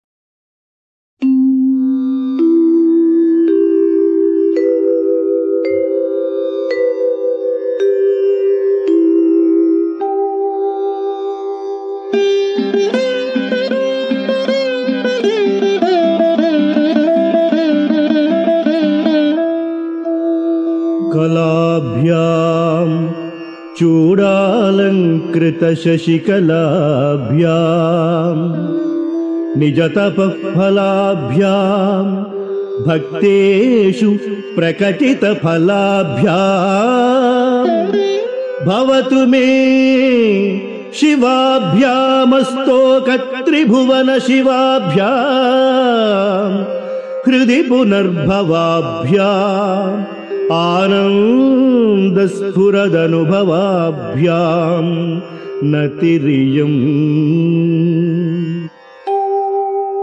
ஆடியோ – Dr. M.பாலமுரளிகிருஷ்ணாவின் தேனிசைக்குரல்.